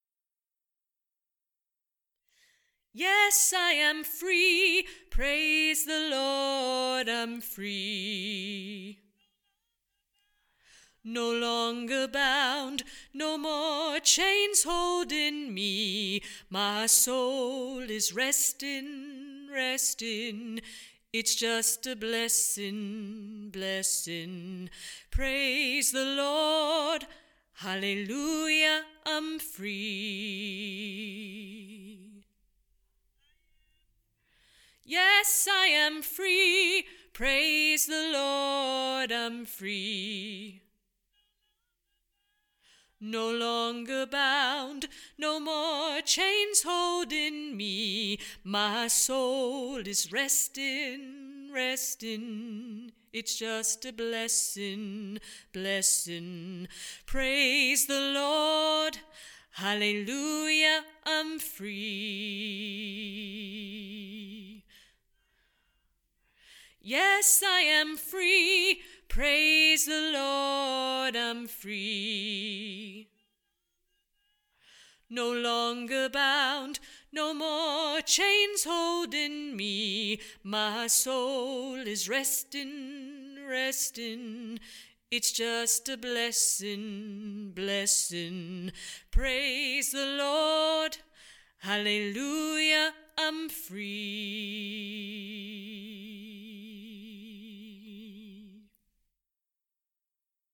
I am Free – Tenor
Genre: Choral.